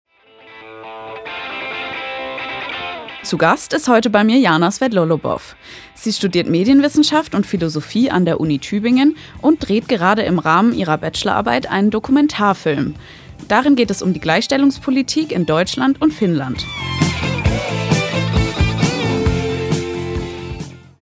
Studiogespräch
Moderation: